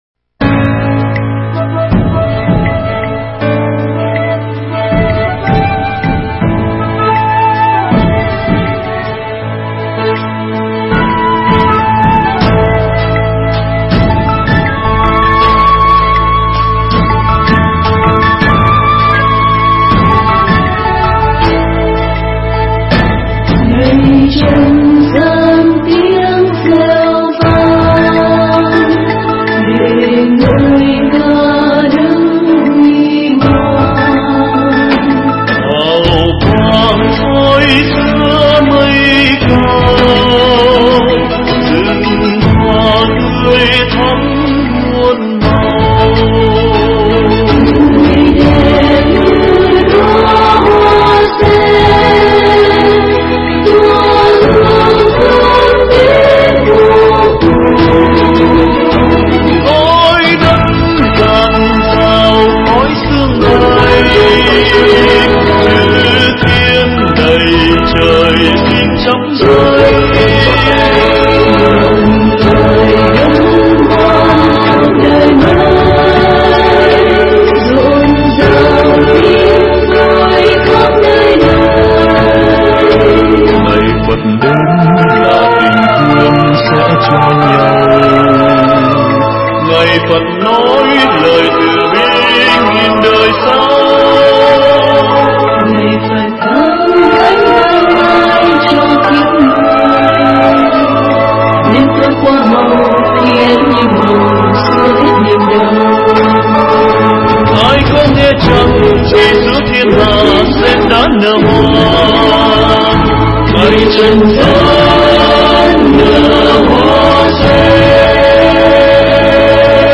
Nghe Mp3 thuyết pháp Vì Hạnh Phúc Cho Đời
Nghe mp3 pháp thoại Vì Hạnh Phúc Cho Đời